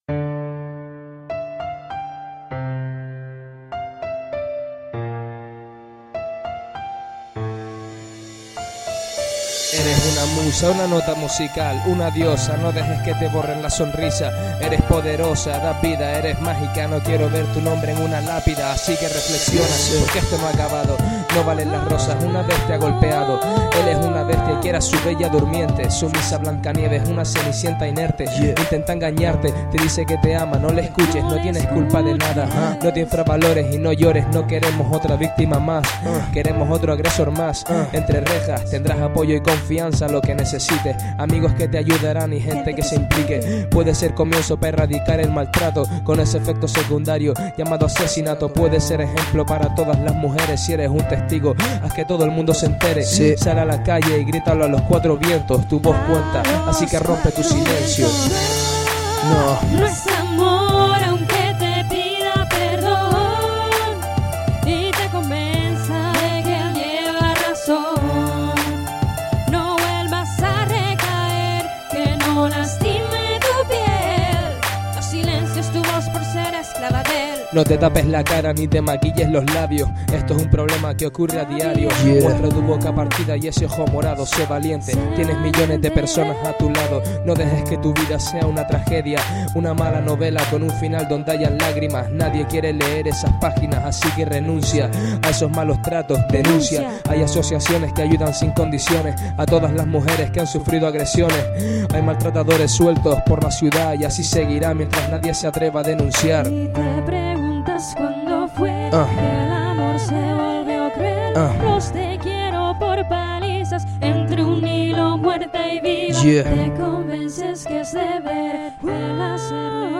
Rap Social